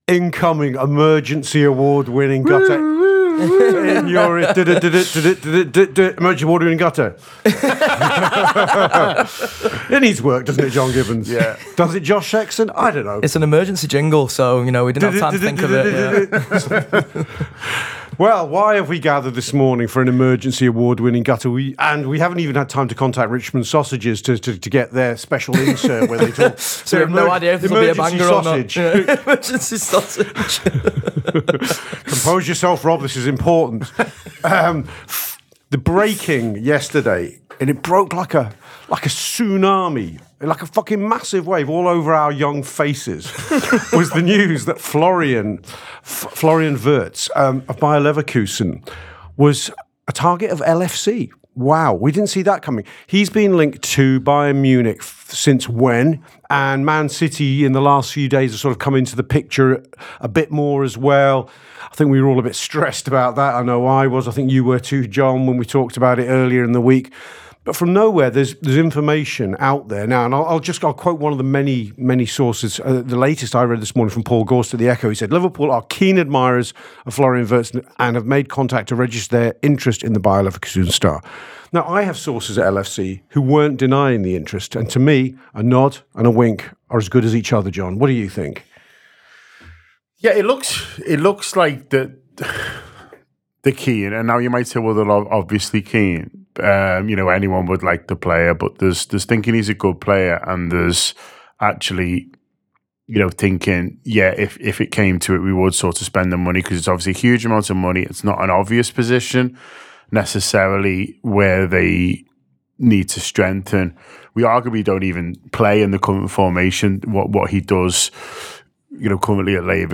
Below is a clip from the show – subscribe for more on the latest Liverpool transfer news…